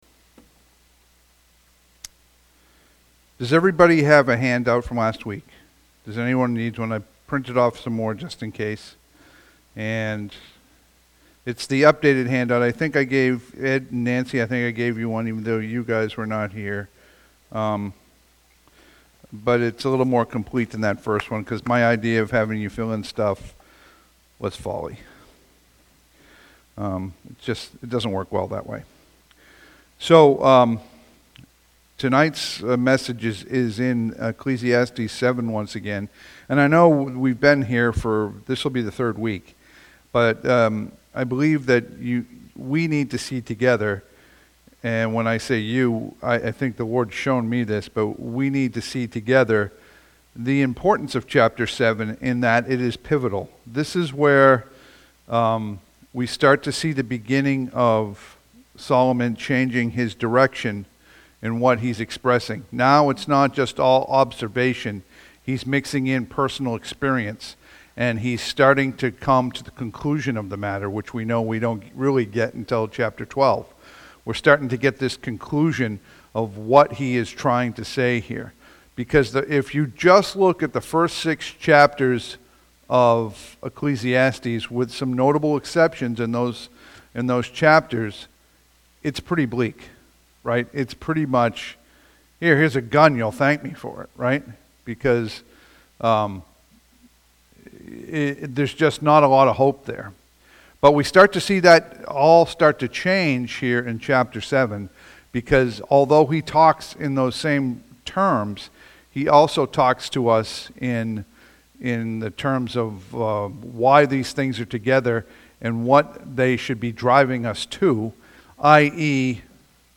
Passage: Ecclesiastes 7:20-29 Service Type: Sunday PM « August 3